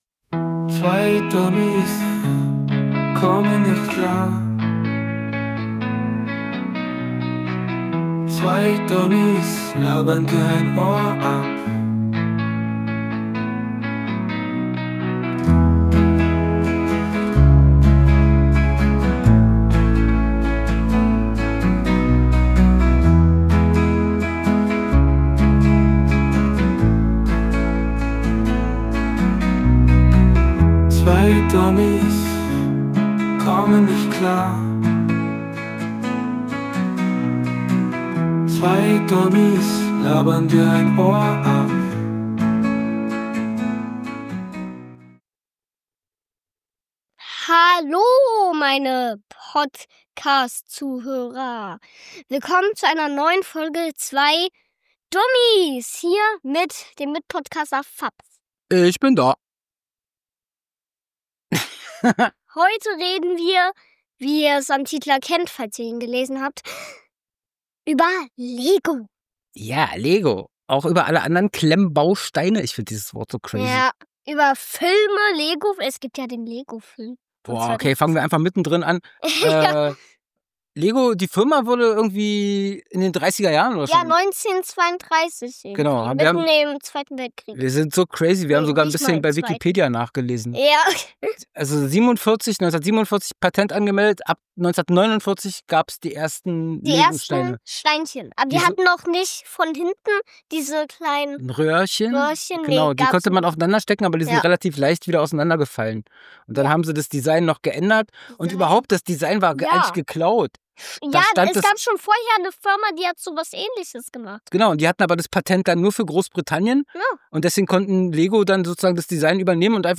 Der Podcast widmet sich in dieser Folge dem Thema Lego und Klemmbausteine, wobei die Hosts in einem lockeren und humorvollen Ton verschiedene Aspekte beleuchten.